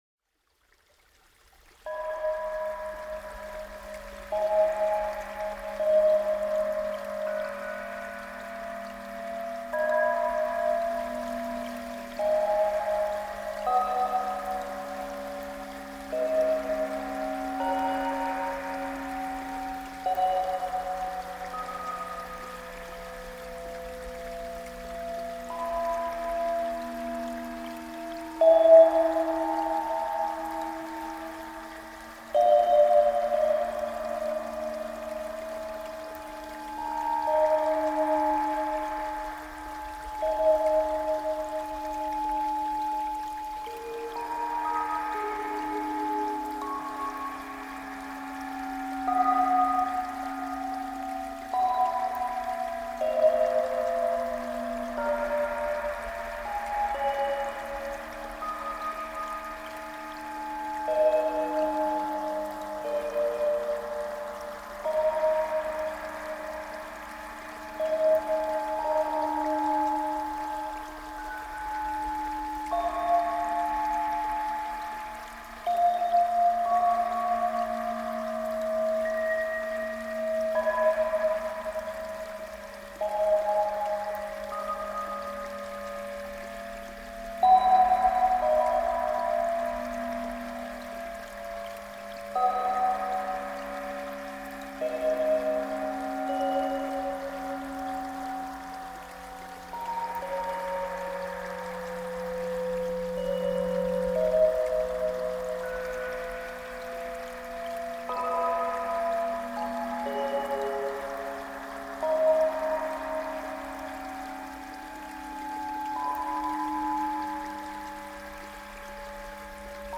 • Category: Sounds of summer